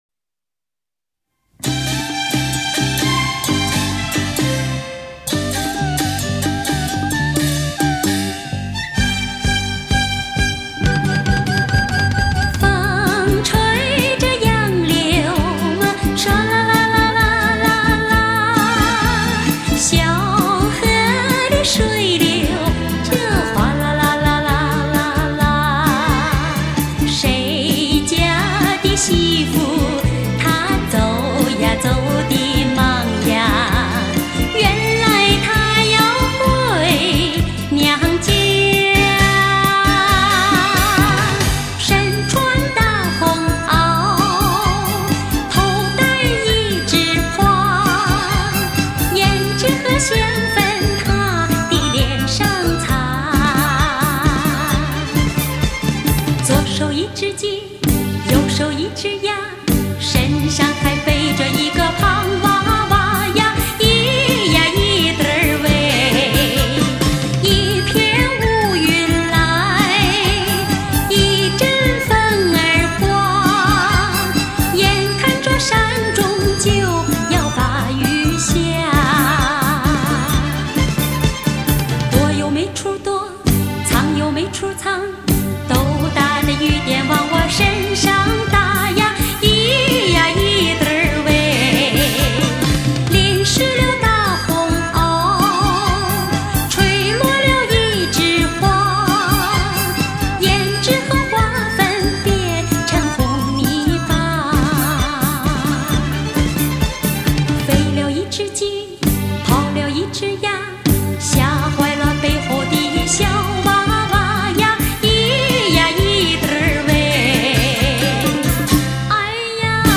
民族歌曲经典之作